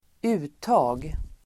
Ladda ner uttalet
Uttal: [²'u:ta:l]